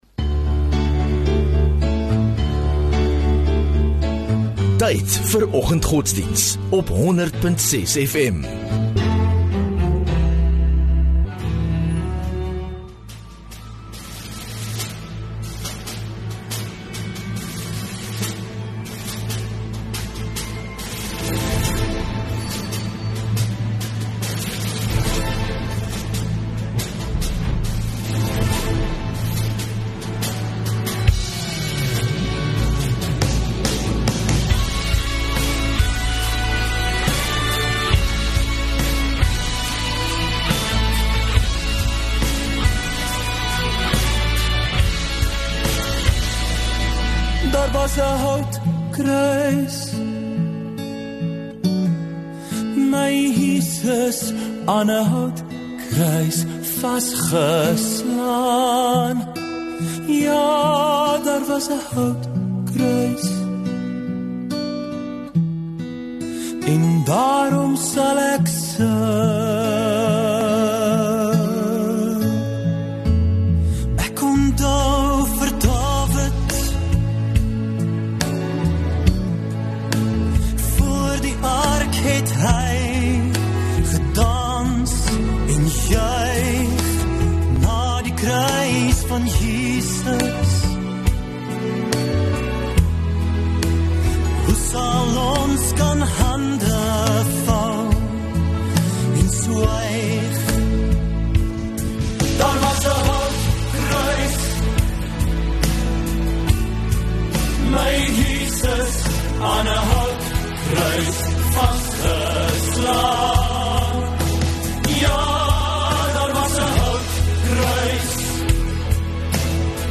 5 Feb Woensdag Oggenddiens